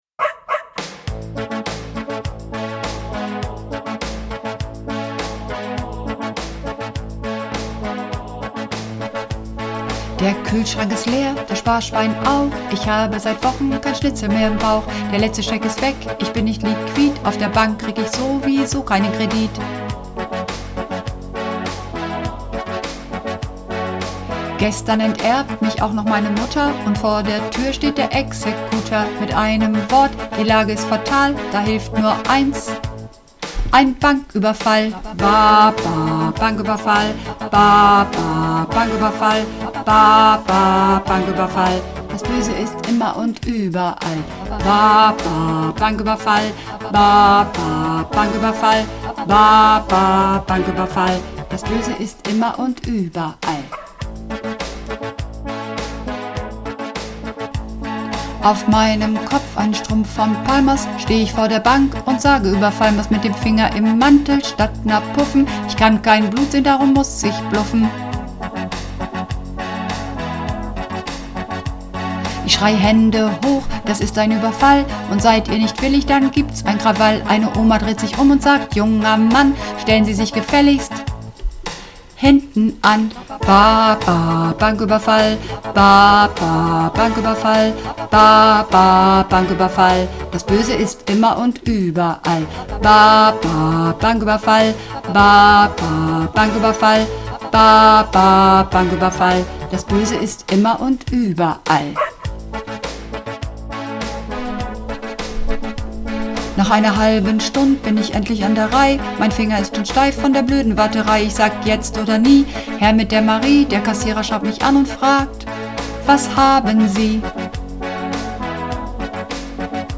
Übungsaufnahmen - Ba-Ba-Banküberfall
Runterladen (Mit rechter Maustaste anklicken, Menübefehl auswählen)   Ba-Ba-Banküberfall (Bass)